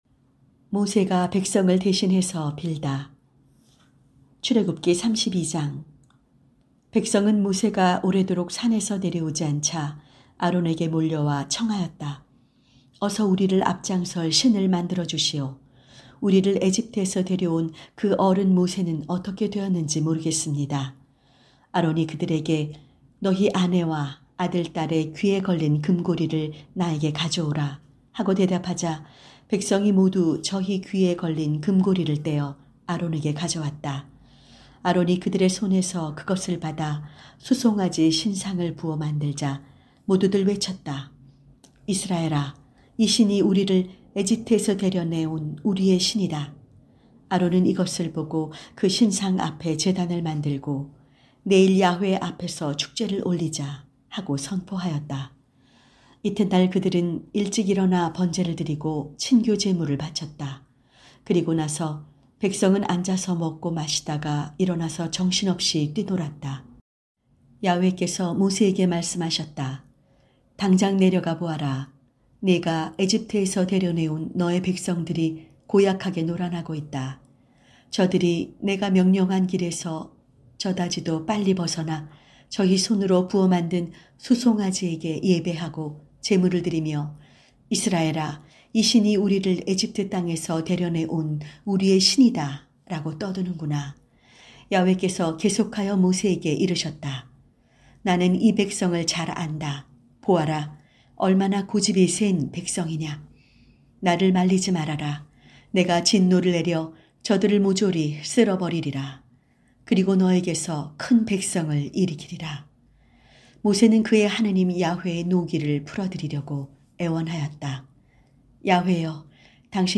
성경 오디오